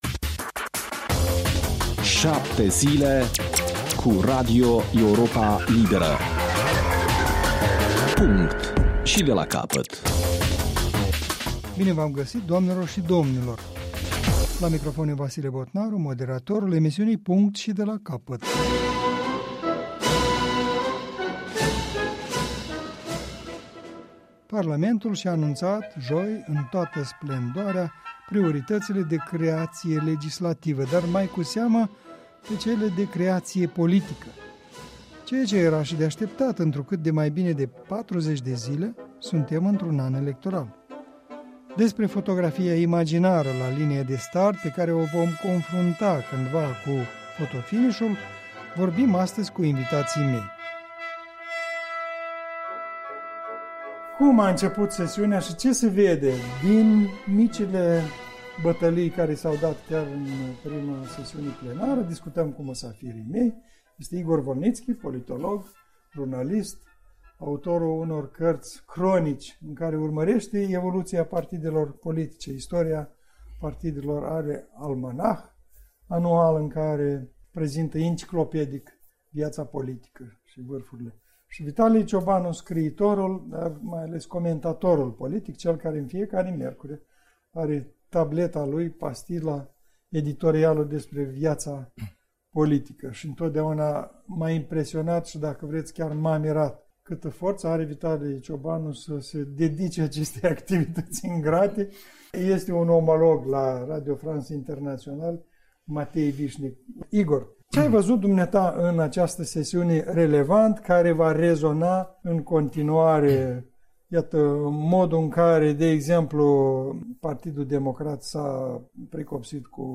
O dezbatere duminicală despre noua sesiune parlamentară și perspectivele ei